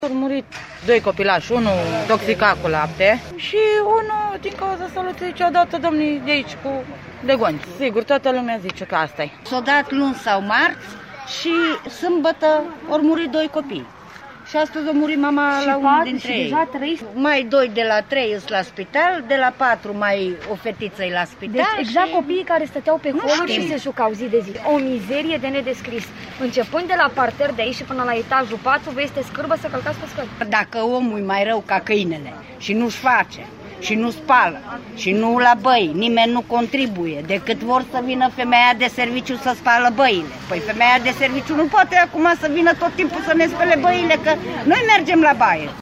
voxuri-strada-miorita.mp3